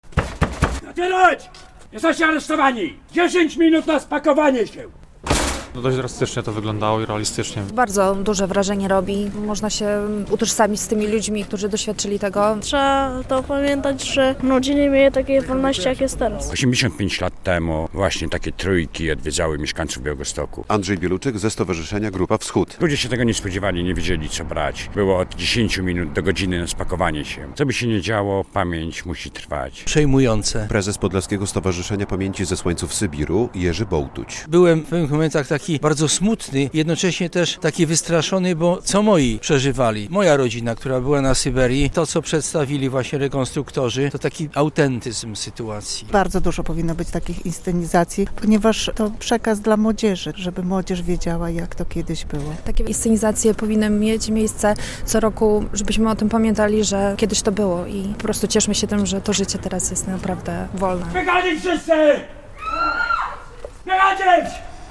Rekonstrukcja wywózek na Sybir - relacja